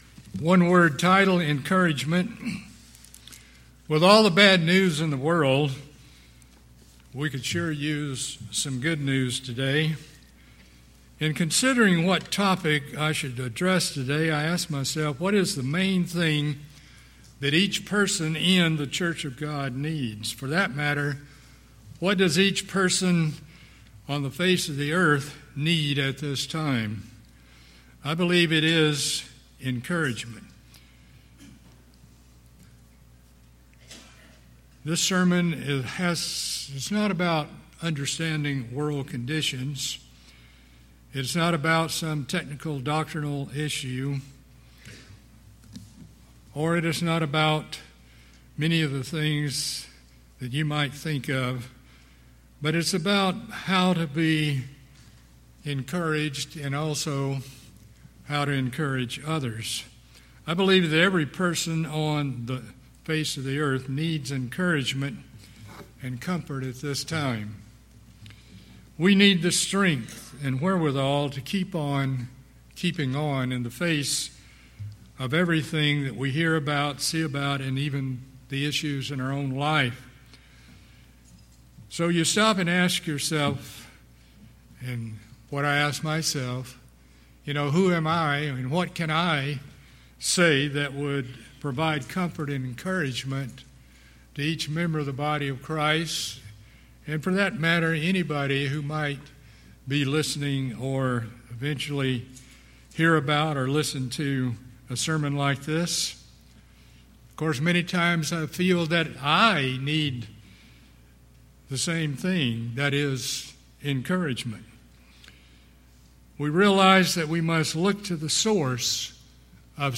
Though we live in a world where discouragement and depression are rampant, God shows us in His word that His people need not be susceptible to either of these. Presented in this sermon are strategies for God's people to remain optimistic, encouraged, and encouraging to others despite the pressures of the world around us.